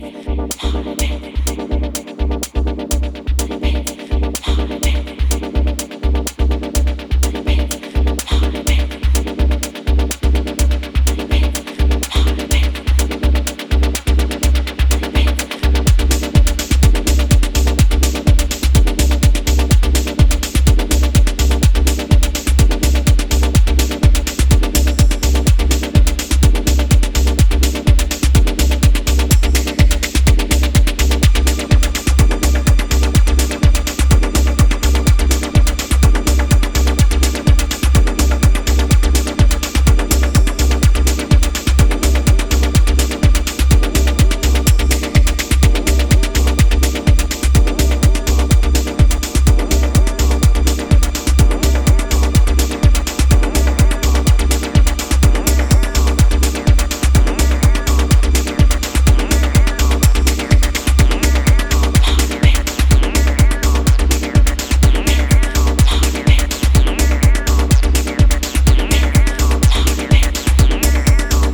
泡立つようなリフやハイトーンのアシッドが深夜帯のフロアに効能する